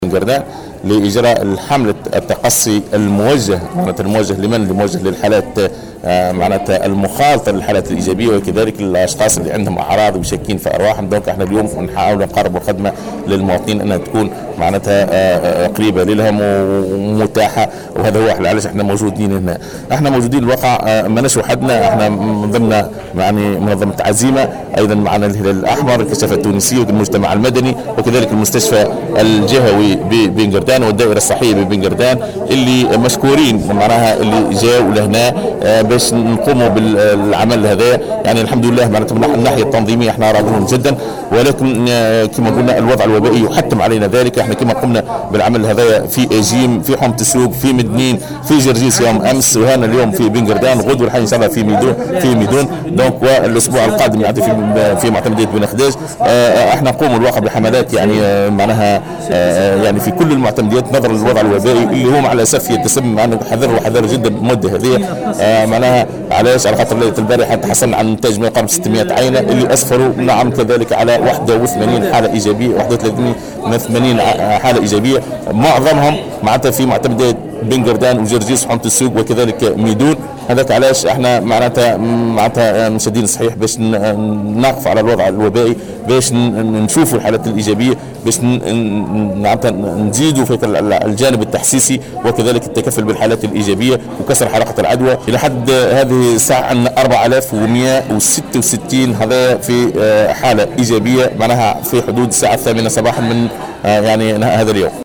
وشدّد في تصريح اليوم لمراسل "الجوهرة أف أم" على هامش يوم مفتوح للتقصي حول فيروس كورونا ببن قردان، على ضرورة التكثيف من حملات التقصي لكسر حلقات العدوى، والتحسيس بأهمية التقيّد بشروط الوقاية.